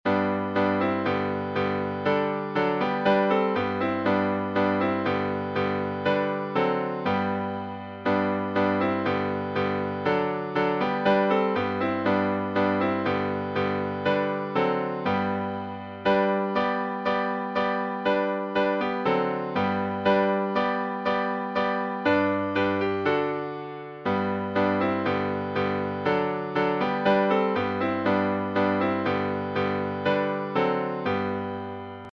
Hymns of praise